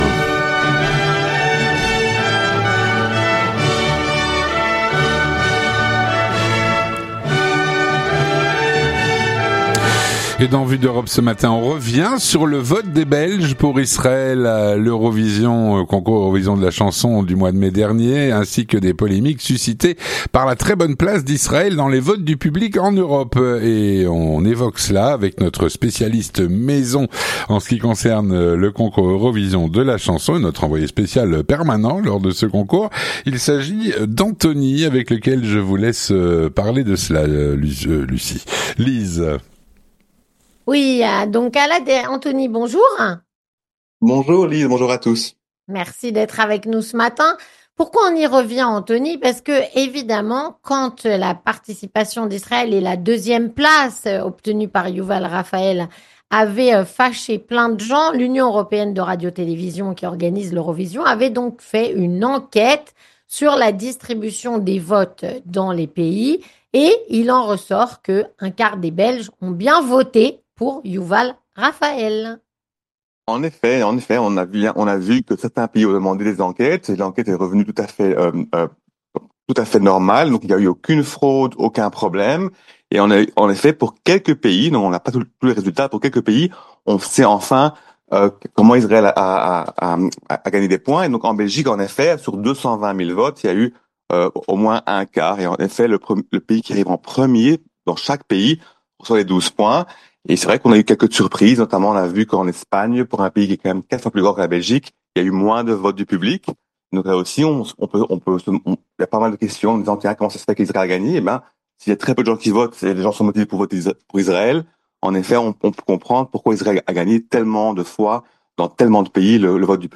3. Les Chroniques de la Matinale
Il est au micro